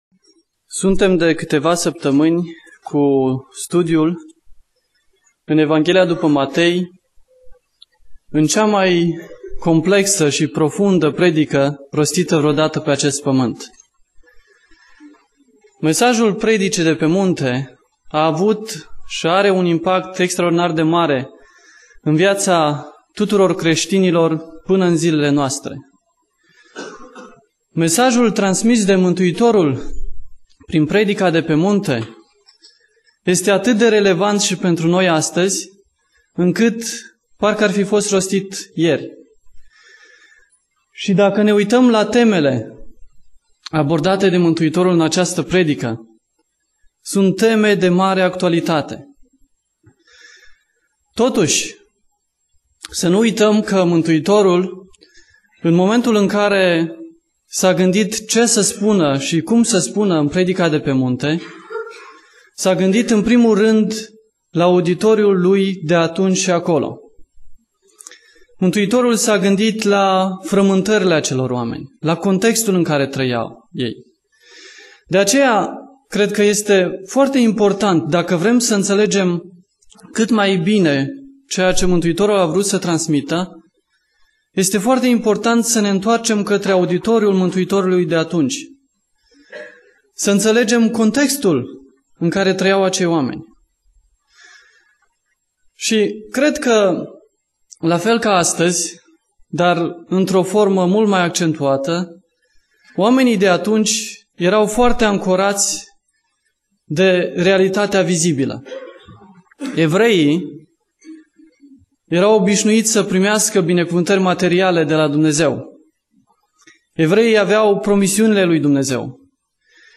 Predica Exegeza - Matei 6b